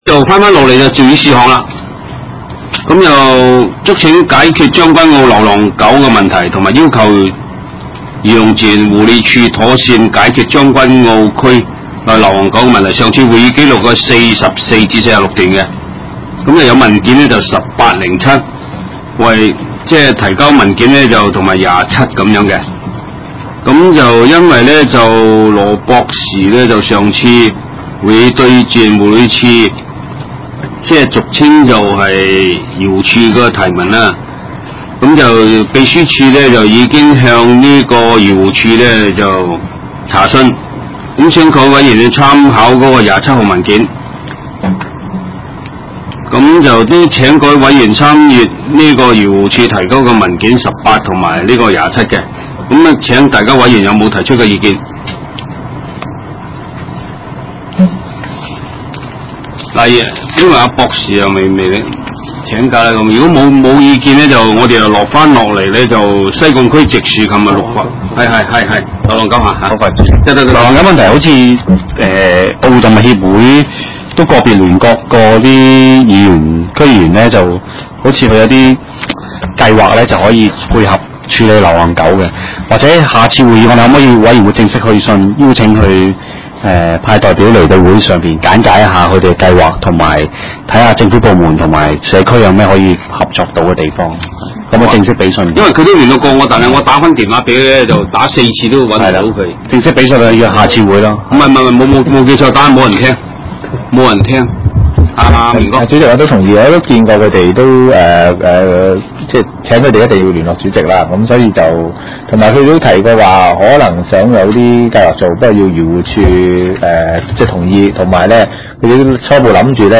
地點：西貢區議會會議室